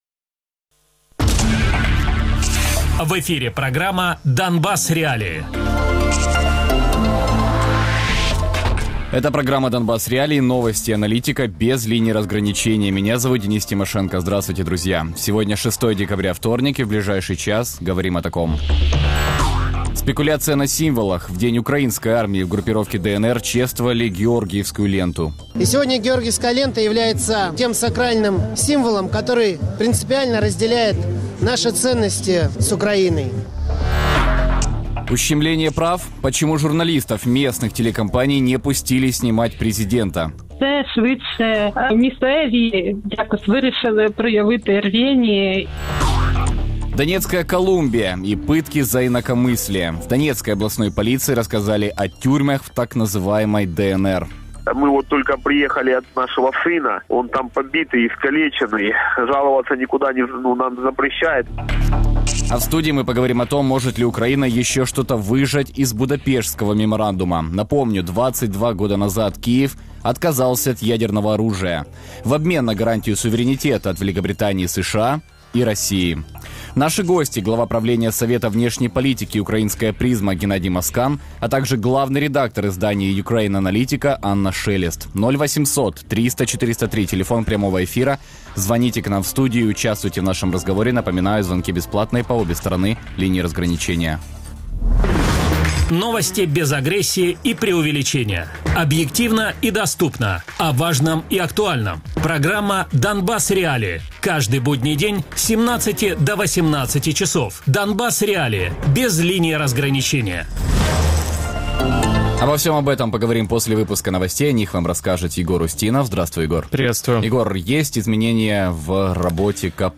Ukraine Analytica Радіопрограма «Донбас.Реалії» - у будні з 17:00 до 18:00.